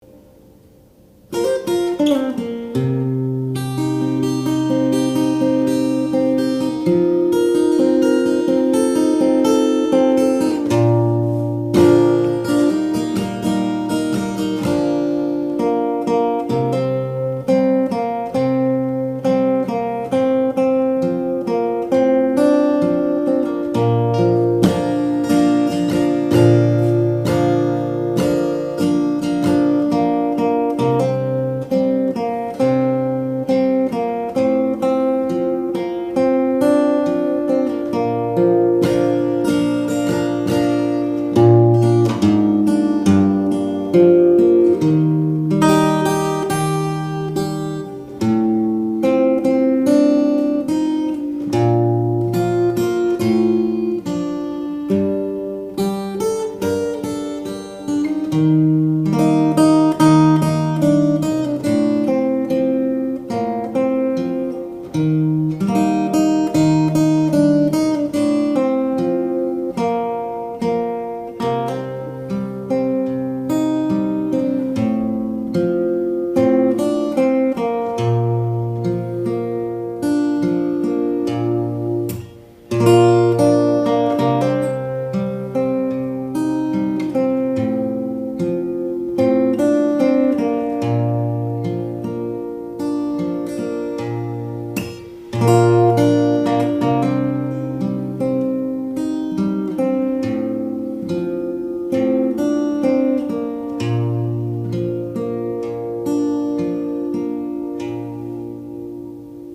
Audio Clip from the Tutorial
Capo 3rd Fret - 4/4 Time